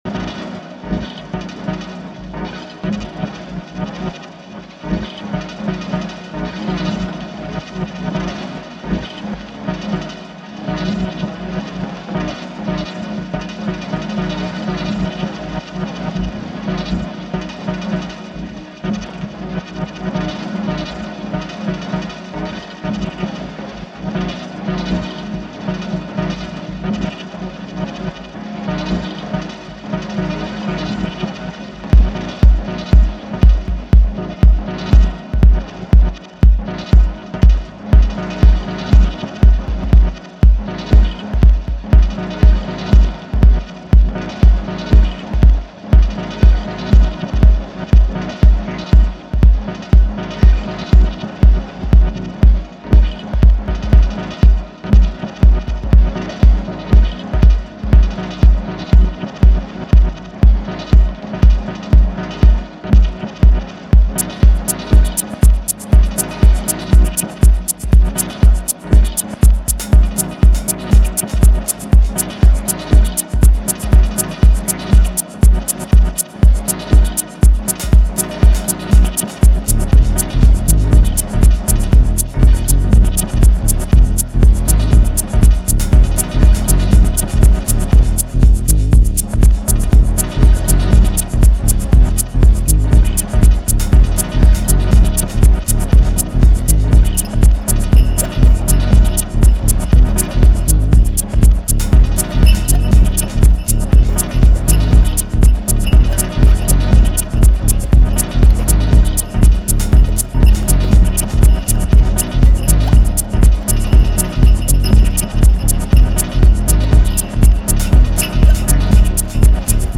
Wanted to try the one pattern setup with effects coming in and out.
This is all just mutes and perform mode.